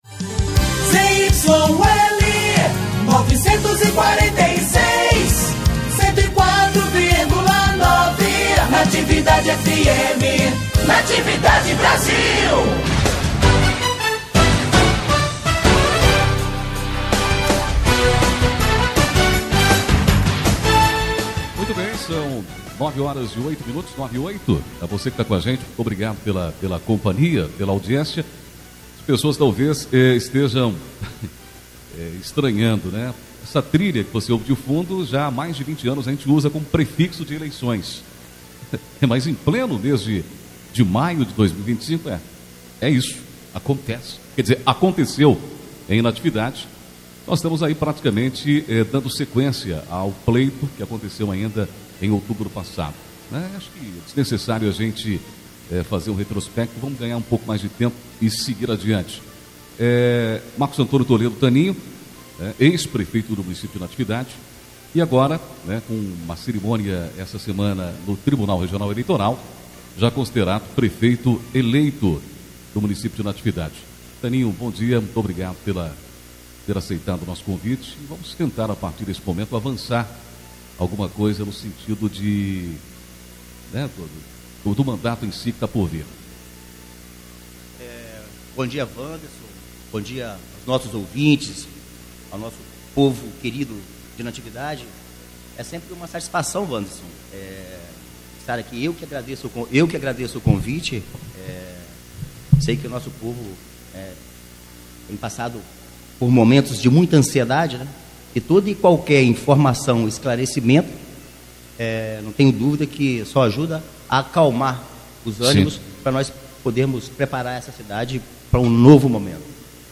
Prefeito eleito fala à Rádio Natividade – OUÇA E VEJA
Na manhã desta quinta-feira (22), o prefeito eleito de Natividade, Marcos Antônio Toledo (Taninho), concedeu entrevista à Rádio Natividade.
22 de maio de 2025 ENTREVISTAS, NATIVIDADE AGORA, VIDEOS